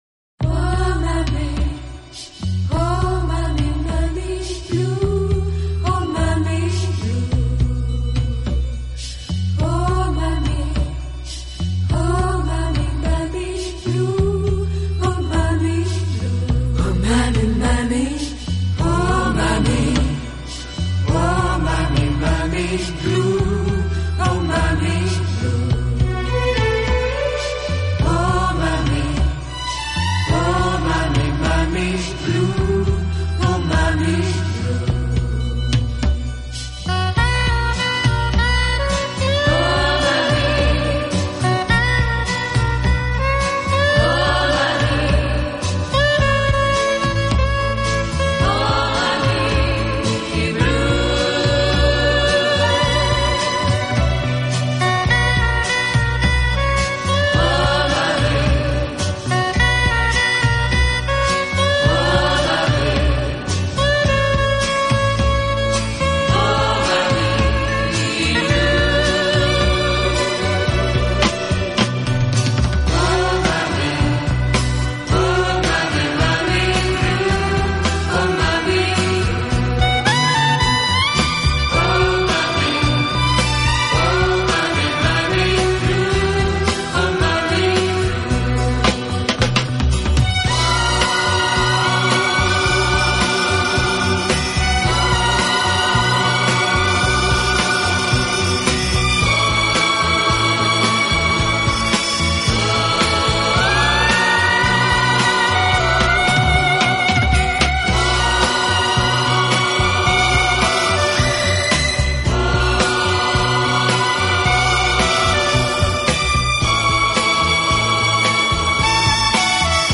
【顶级轻音乐】
“清新华丽，浪漫迷人”